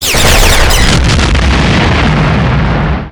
sdlshot.wav